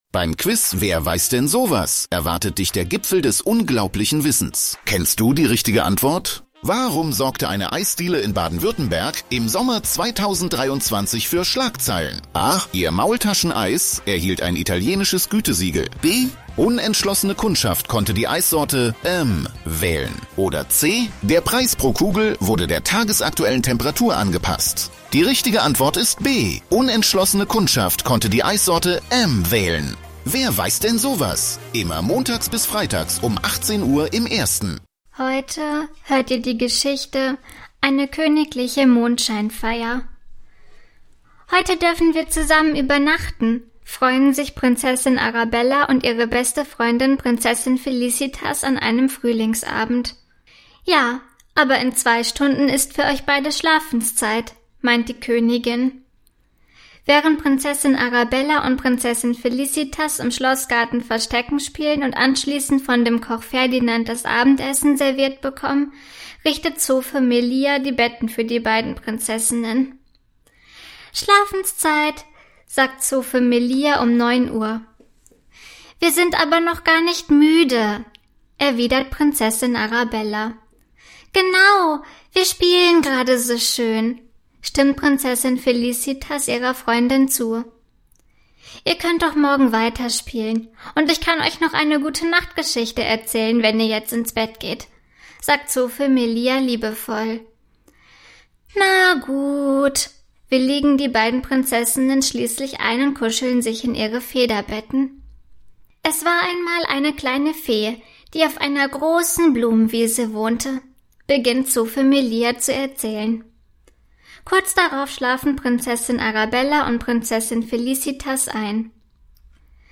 Kindergeschichten mit garantiertem Happy End